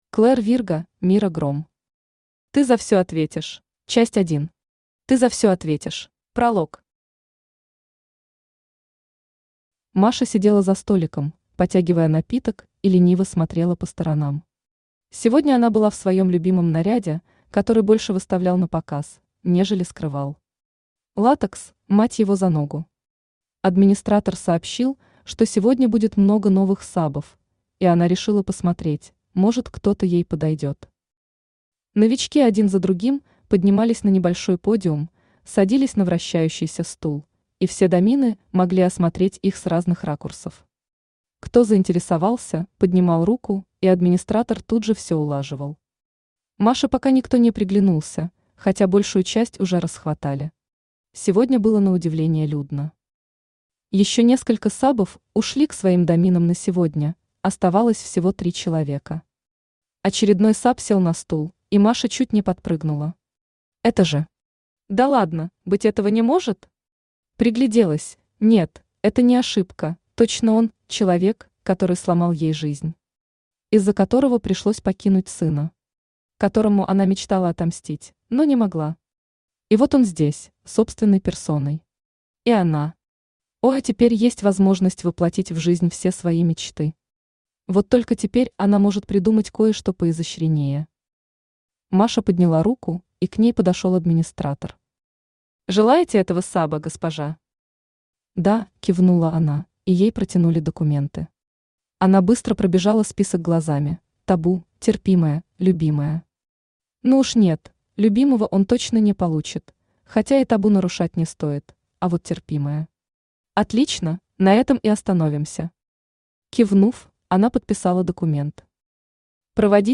Автор Клэр Вирго Читает аудиокнигу Авточтец ЛитРес.